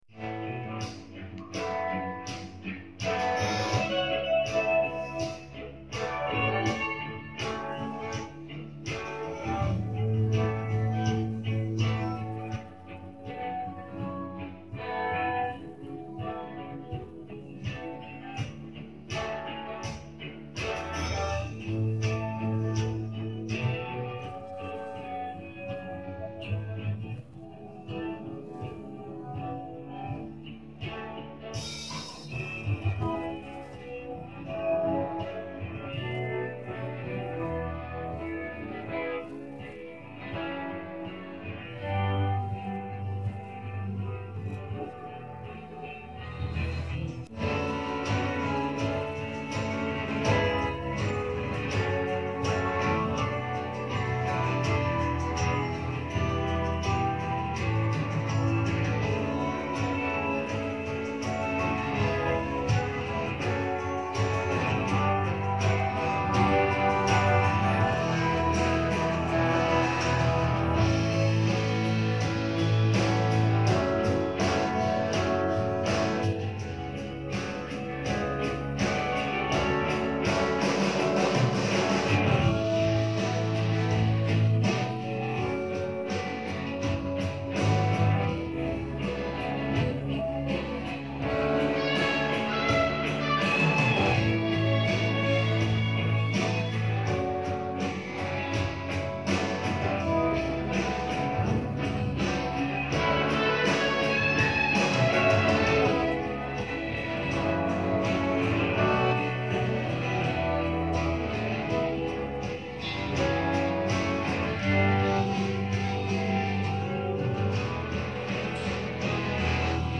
Nhạc nền